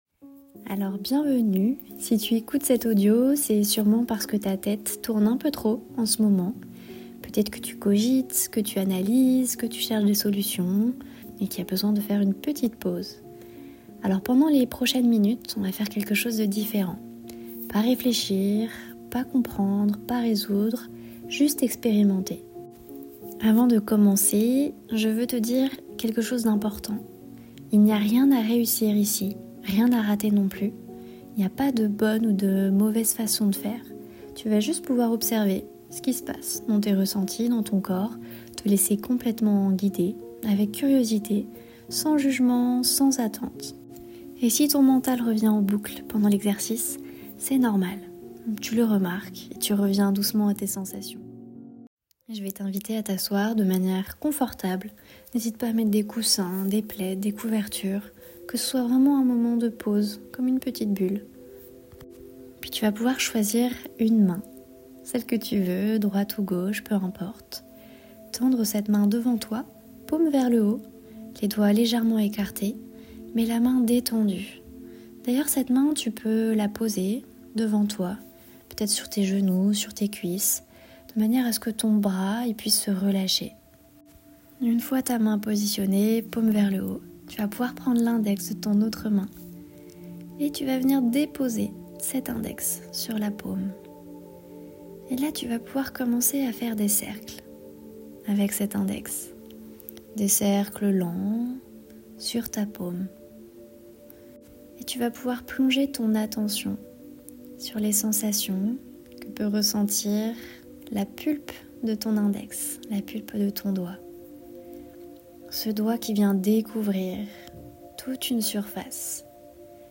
Cet audio guidé gratuit de 5 minutes est une invitation à faire une pause.
Guidé par ma voix.